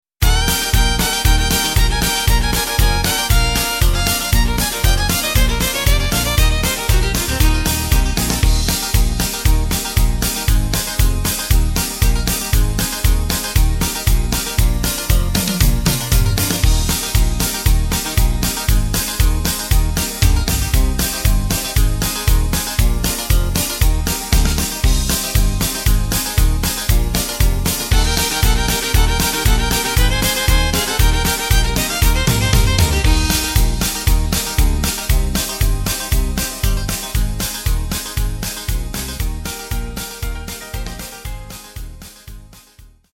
Takt: 2/4 Tempo: 117.00 Tonart: Eb
Country-Beat